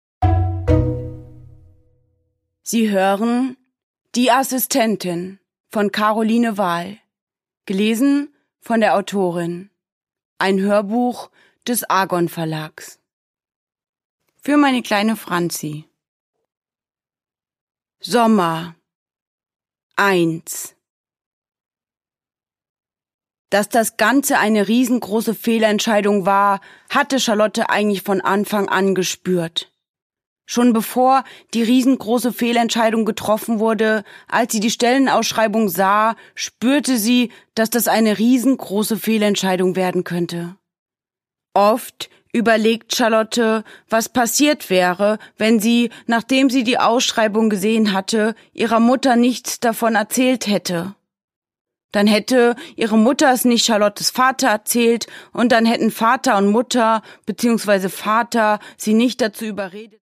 Caroline Wahl: Die Assistentin (Ungekürzte Lesung)
Produkttyp: Hörbuch-Download
Gelesen von: Caroline Wahl